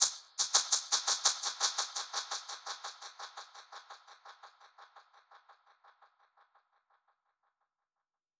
DPFX_PercHit_B_85-06.wav